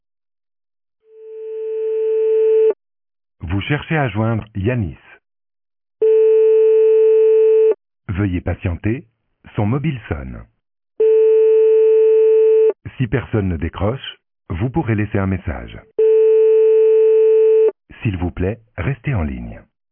Message Classique: Veuillez Patienter